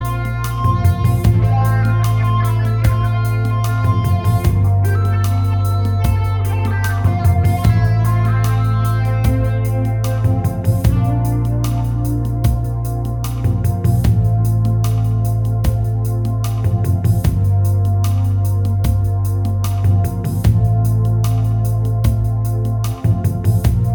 Minus Main Guitar Rock 4:20 Buy £1.50